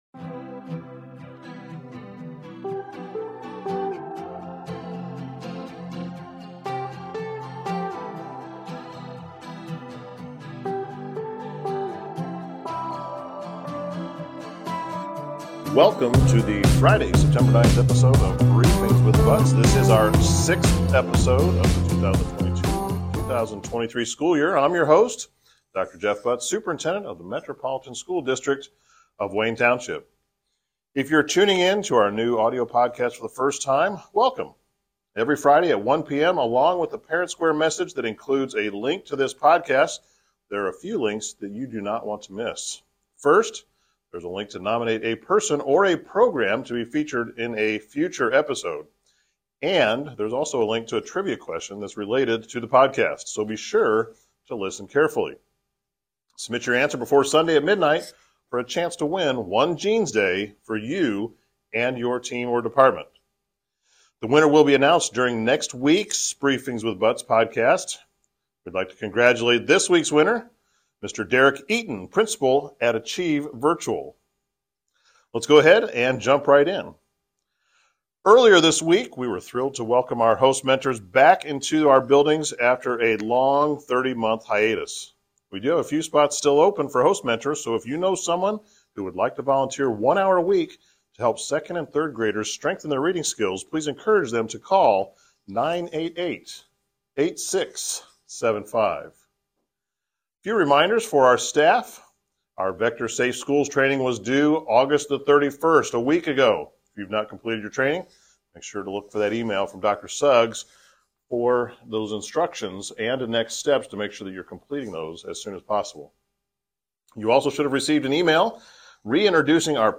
This episode of Briefings with Butts has information for the staff and the community from Dr. Jeff Butts, Superintendent of the MSD of Wayne Township. This week's spotlight interview is on Thursday night's LAP Family Night.